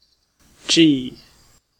Ääntäminen
letter name: IPA : /ˈdʒiː/